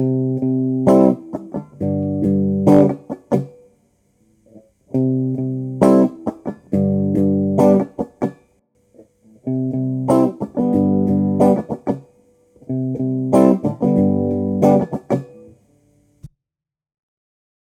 Guitare 1 : Rythmique
Ainsi, on obtient un son étouffé.